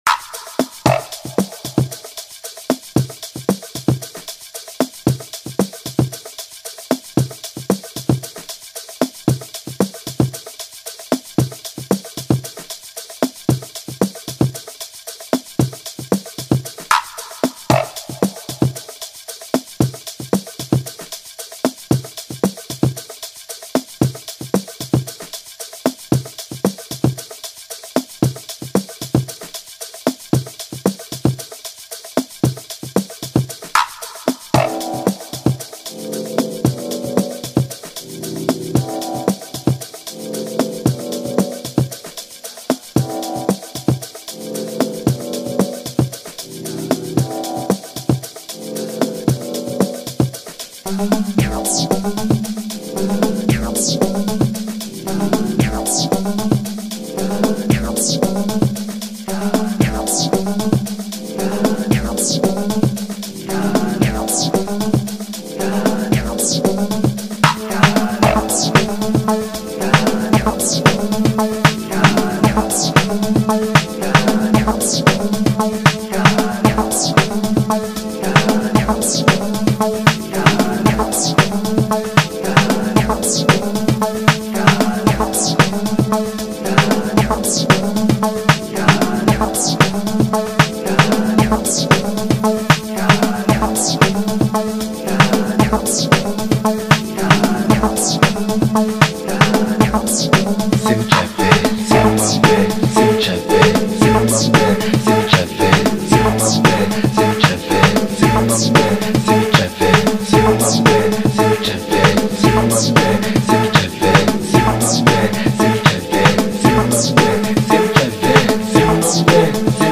a distinct Amapiano sound